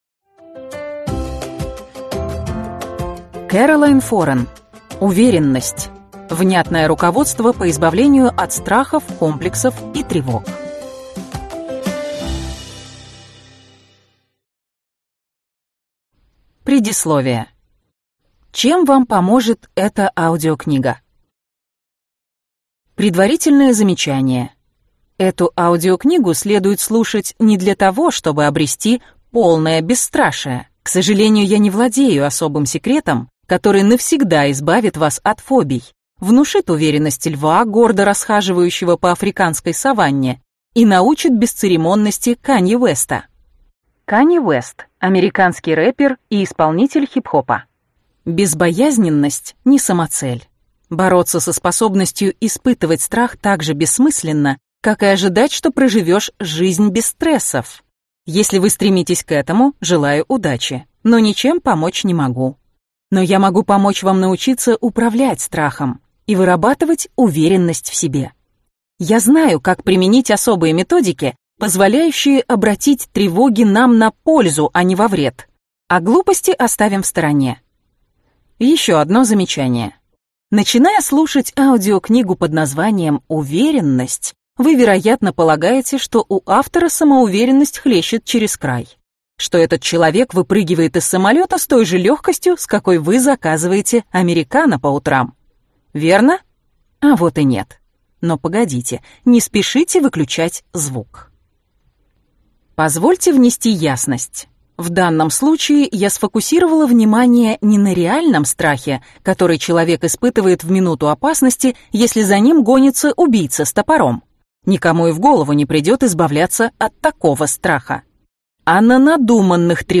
Аудиокнига Уверенность. Внятное руководство по избавлению от страхов, комплексов и тревог | Библиотека аудиокниг